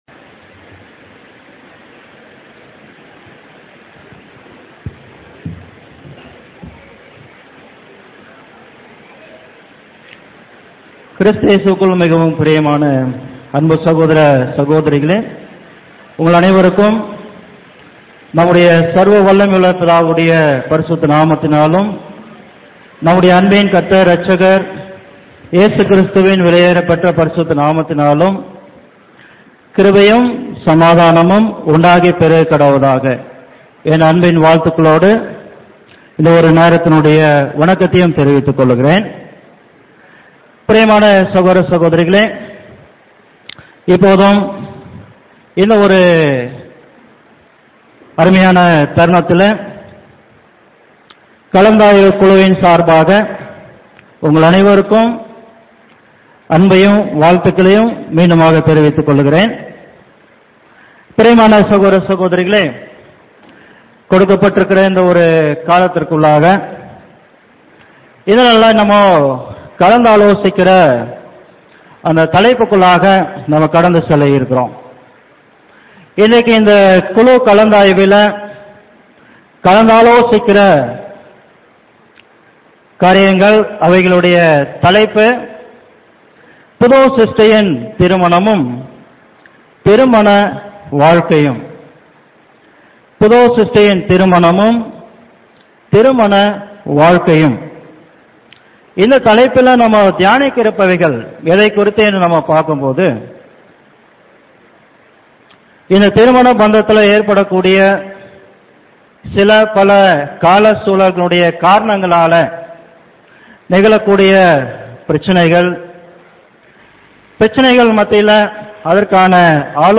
Panel_Discussion.mp3